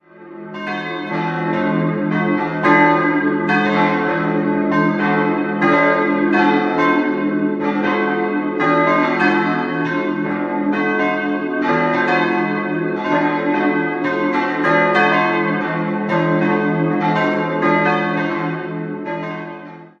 Der Turm ist bezeichnet mit dem Jahr 1498 und hat spitzbogige, gekoppelte Schallfenster und Maßwerkfenster im Giebel. 4-stimmiges Geläute: d'-fis'-a'-c'' Alle vier Gussstahlglocken wurden 1921 von den Böhlerweken in Kapfenberg gegossen.